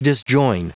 Prononciation du mot disjoin en anglais (fichier audio)
Prononciation du mot : disjoin